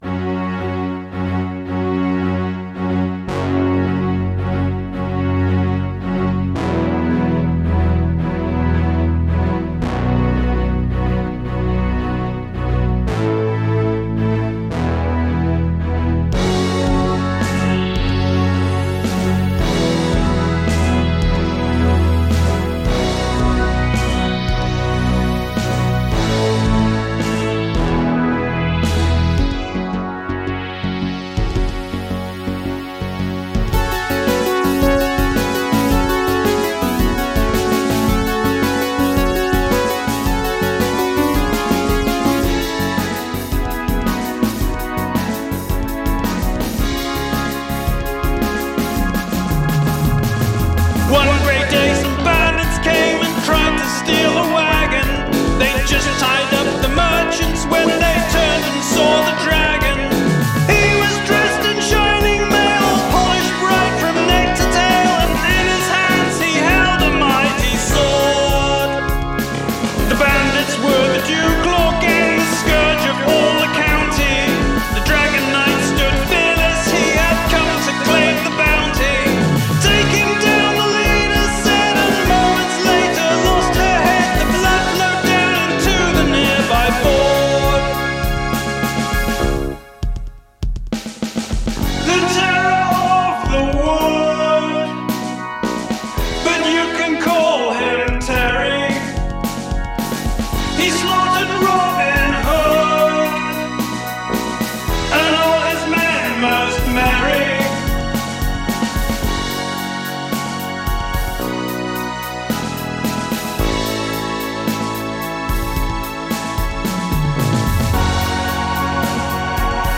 This was recorded on 2" 24-track with the vocals and bass tracked to 1/2" 8-track first. Mixing was done to 1/4" stereo on a Studer A807 as a nice test after recapping the audio boards to solve a problem with loss of bass. One tape edit was needed.